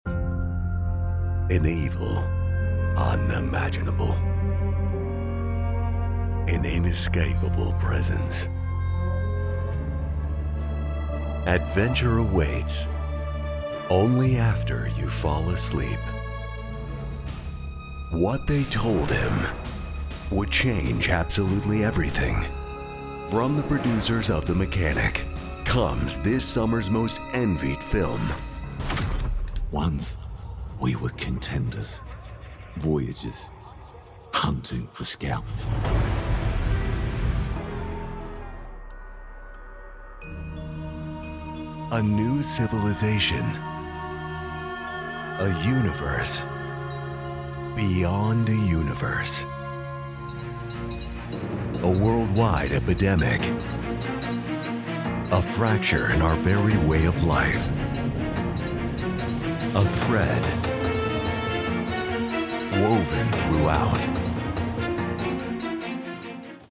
Young Adult, Adult
cinema trailer